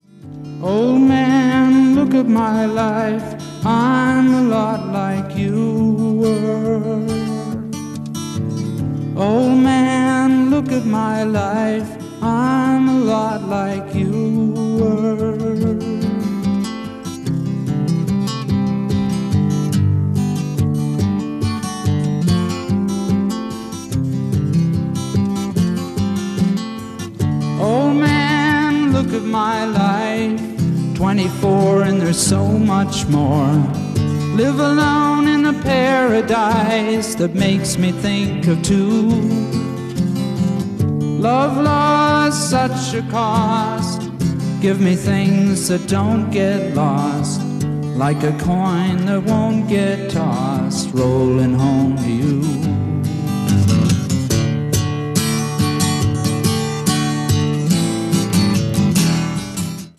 Genres: Rock, Folk, Country